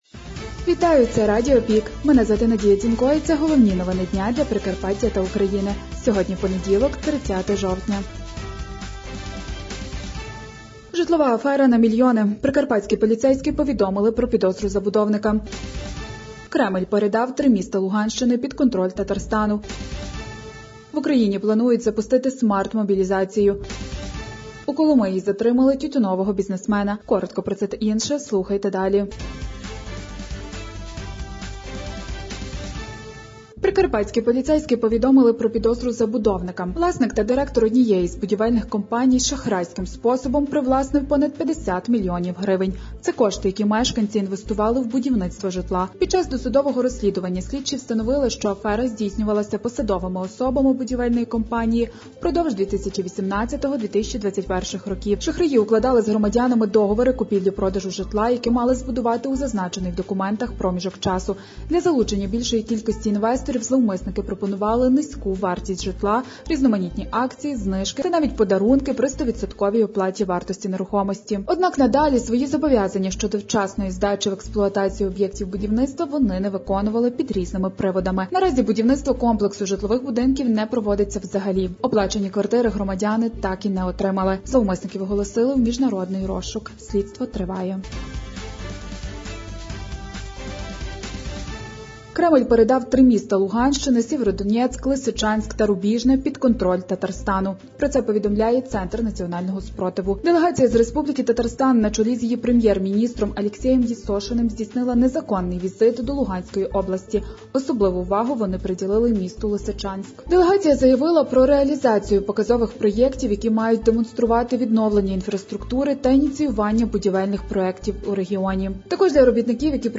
Зібрали актуальне за день у радіоформаті